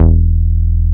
R MOOG E2F.wav